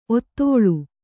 ♪ ottōḷu